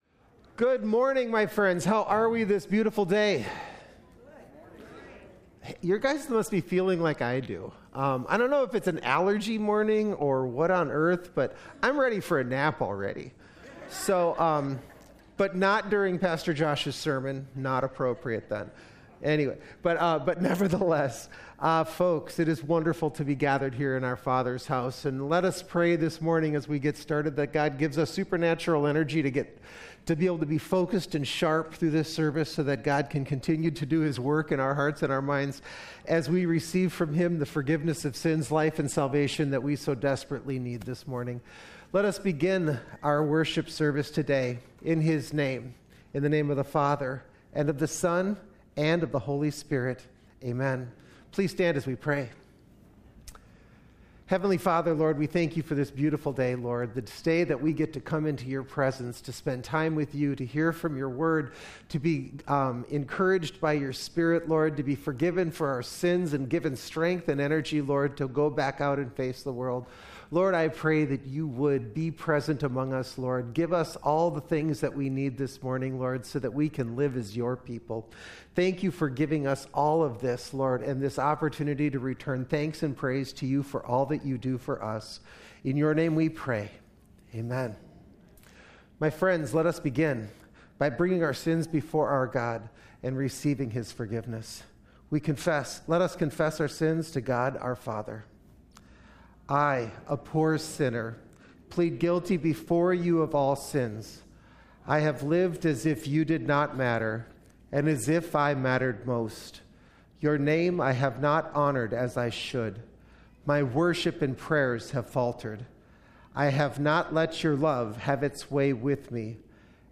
2025-October-5-Complete-Service.mp3